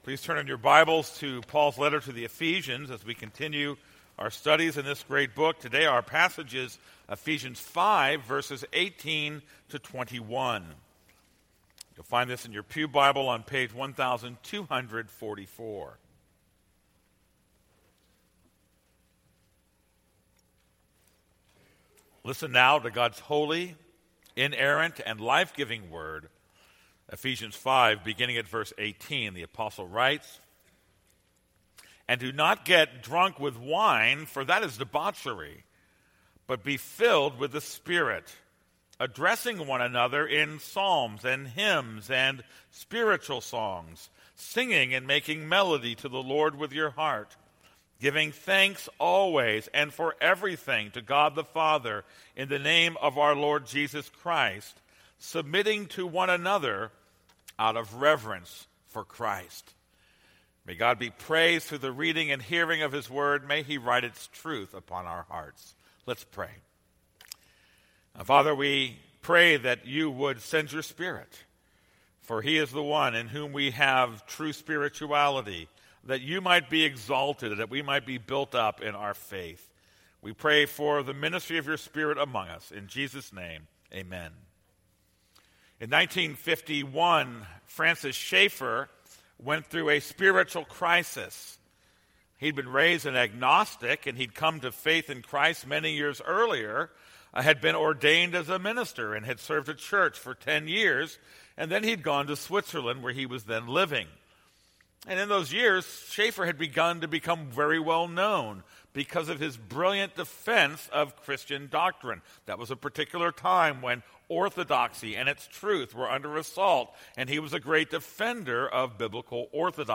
This is a sermon on Ephesians 5:18-21.